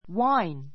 wine A2 wáin ワ イン 名詞 ワイン, ぶどう酒 I like red [white] wine.